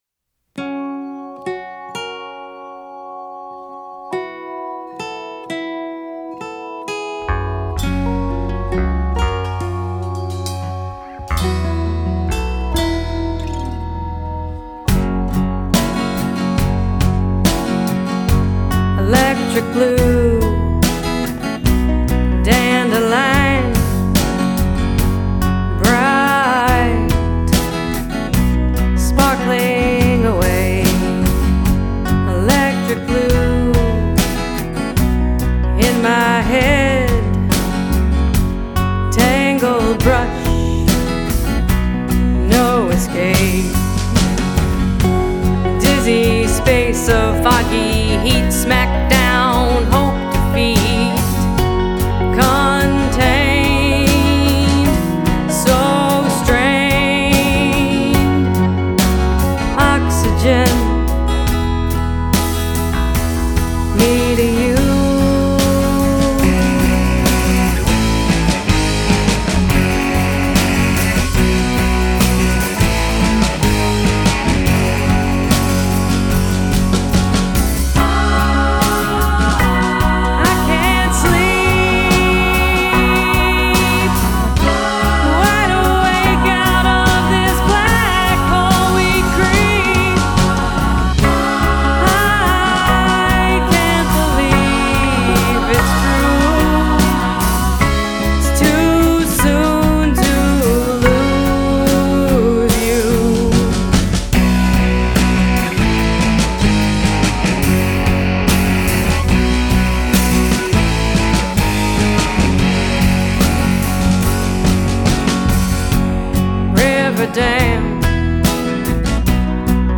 a late-blooming, retro-sounding blast of 1960s cool.